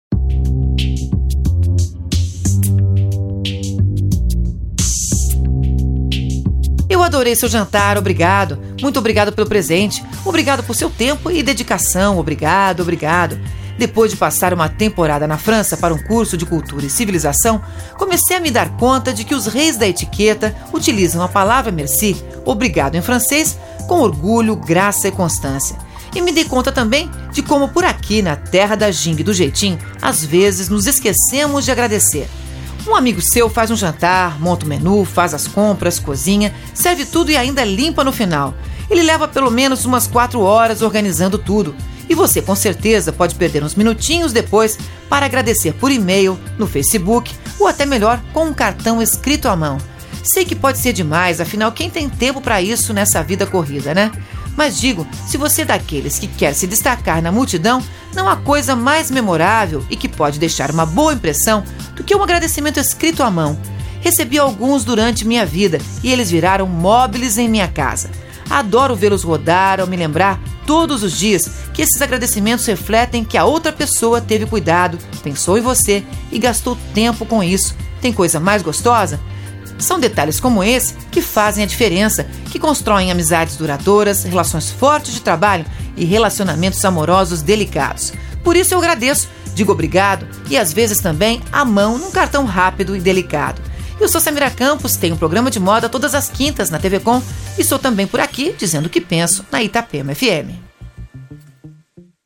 comentário sobre moda e comportamento para a Itapema FM